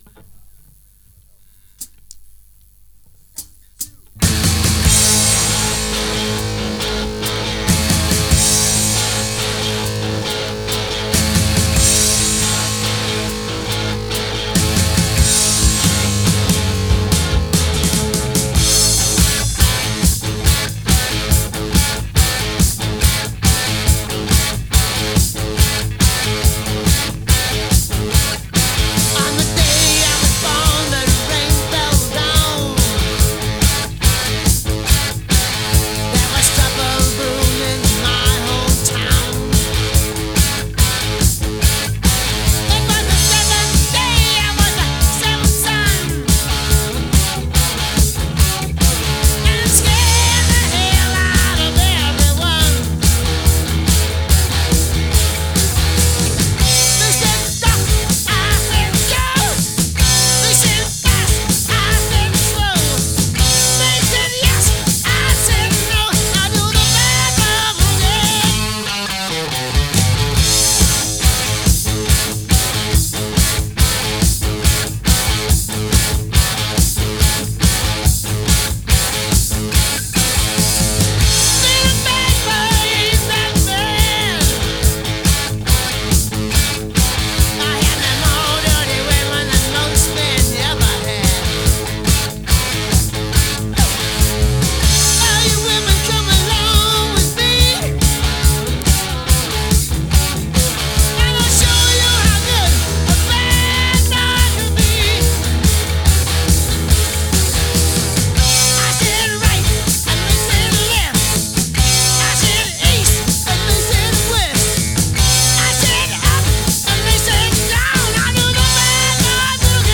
Мой ремастер!